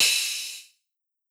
Lab Open Hihat.wav